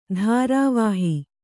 ♪ dhārā vāhi